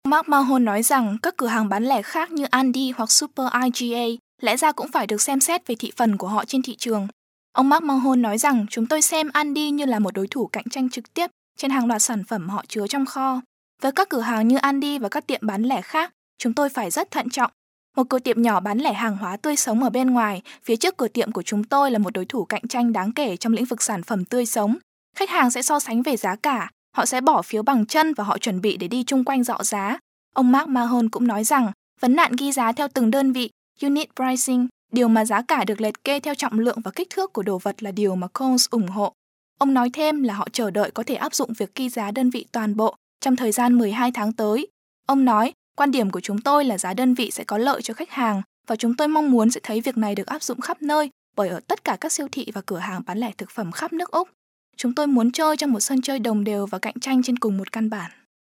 Sprecherin vietnamesisch für Werbung, TV, Radio, Industriefilme und Podcasts
Sprechprobe: Werbung (Muttersprache):
Professional female vietnamese voice over artist